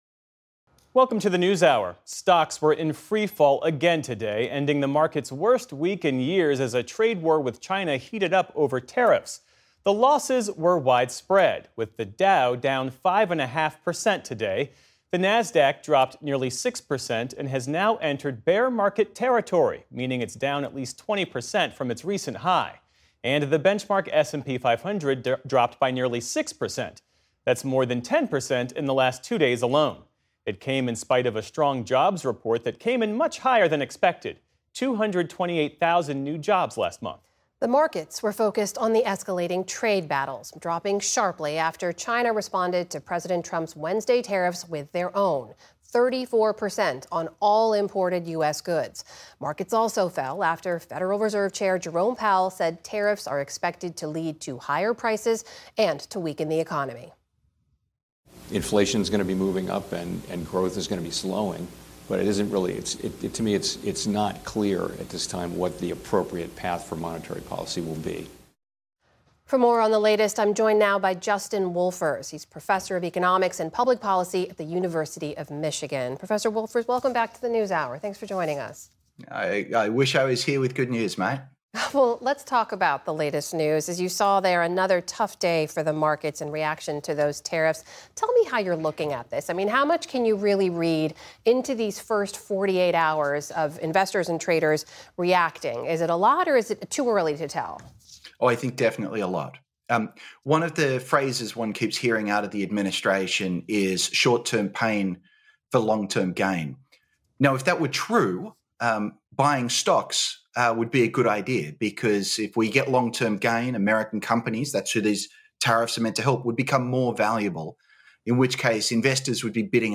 News, Daily News
Amna Nawaz discussed more with Justin Wolfers.